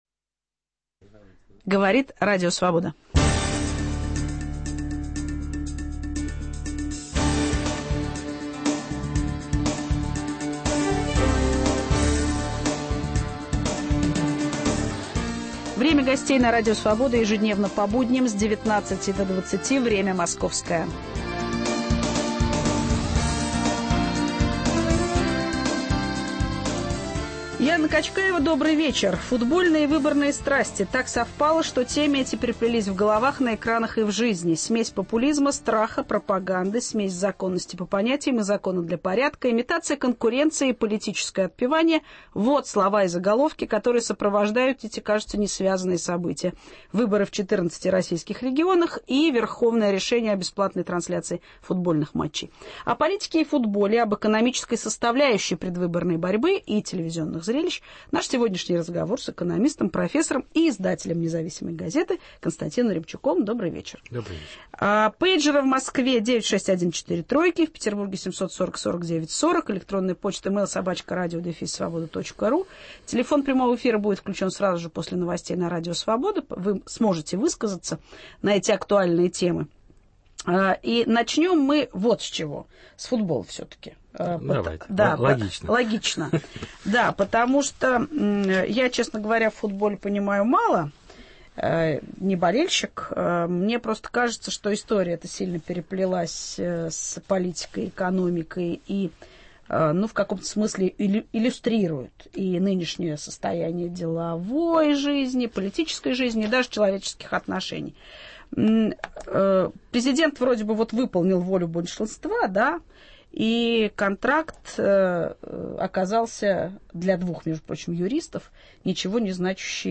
Гость - профессор, издатель "Независимой газеты" Константин Ремчуков.